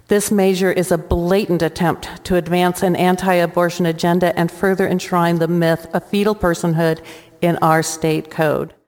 Democrats like Representative Beth Wessell-Kroschell of Ames say the change could make invitro fertilization illegal and they cited the recent Alabama supreme court ruling that embryos are children.